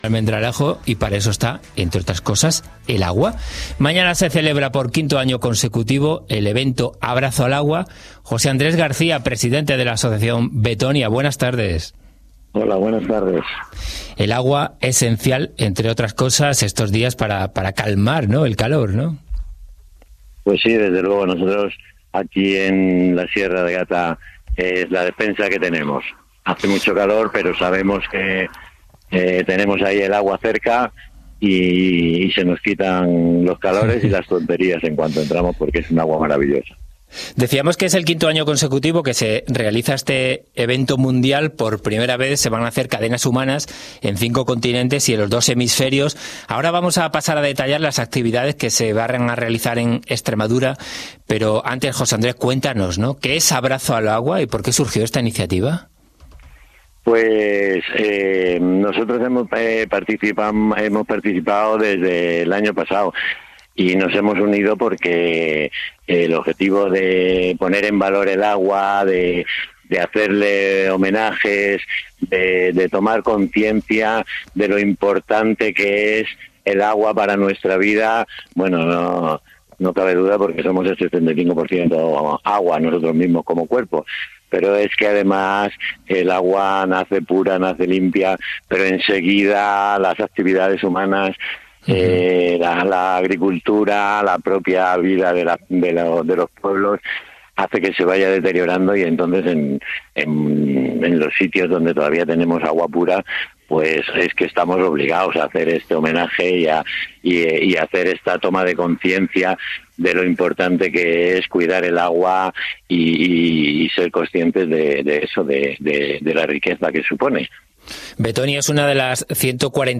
*Entrevista y explicación en El Sol Sale por el Oeste de Canal Extremadura
elsolsaleporeloeste-entrevista-vettonia-agua.mp3